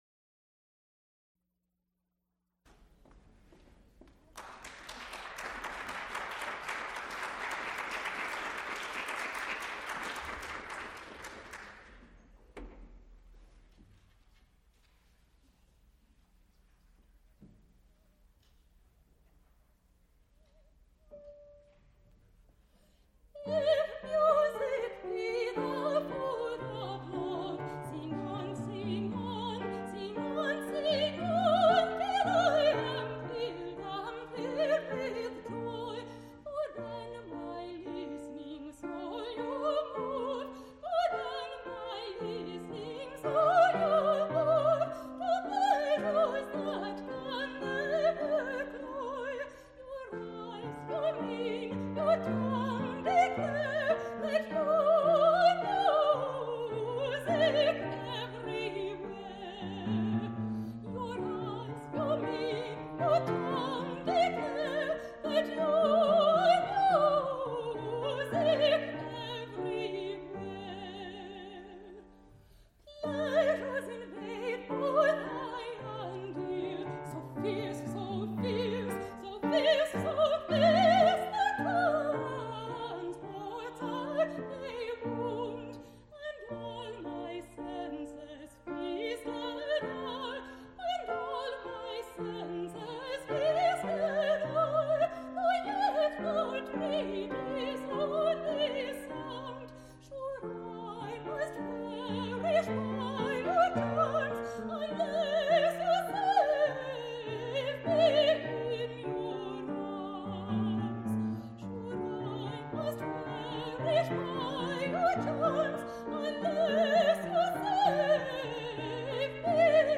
Recorded live February 13, 1980, Frick Fine Arts Auditorium, University of Pittsburgh.
analog, quarter track, 7 1/2 ips
musical performances
Songs (High voice) with piano Song cycles